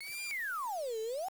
slide.wav